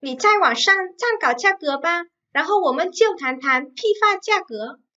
Nǐ zài wǎngshàng cānkǎo jiàgé ba, ránhòu wǒmen jiù tán tan pīfā jiàgé.
Nỉ chai goảng sang chan khảo cha cứa pa, rán hâu ủa mân chiêu thán than pi pha cha cứa.